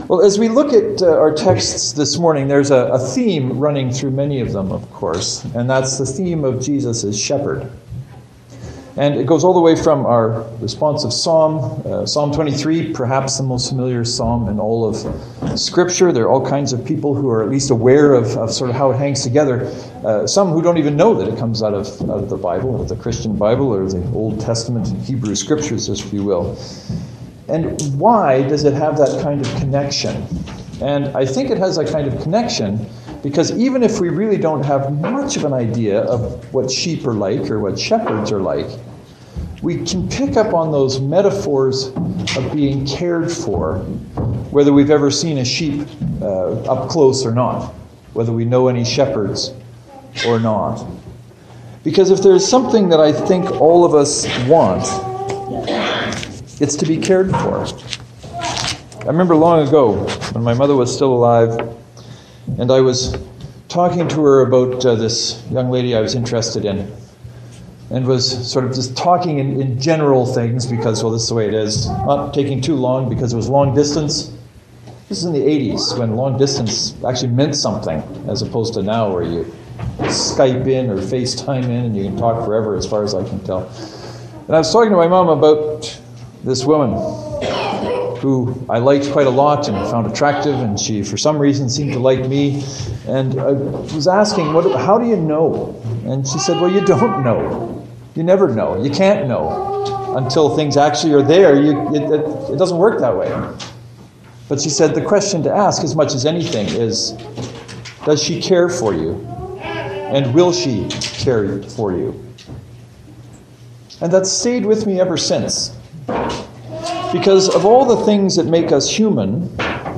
There is only one sermon because this Sunday was a joint service.
The service is held in the Community Centre, which explains the different sound. You will also hear more kids being kids and I hope you’re okay with that.